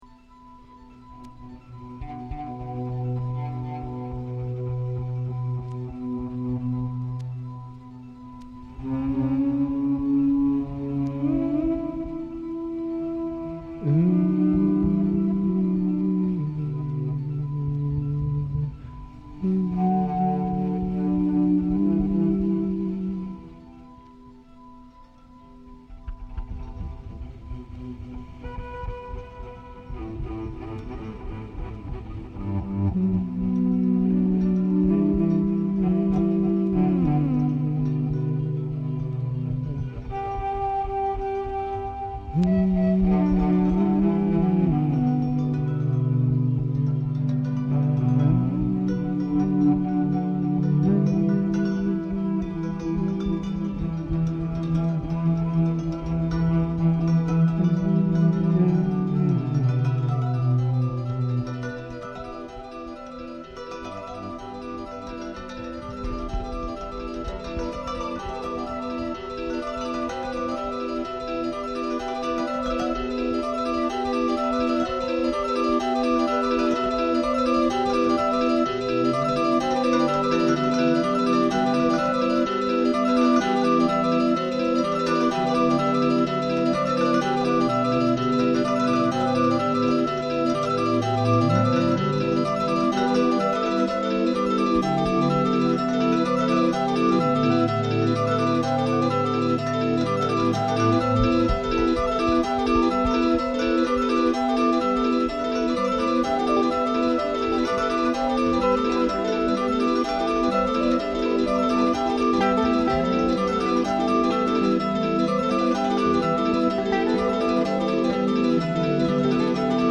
Opera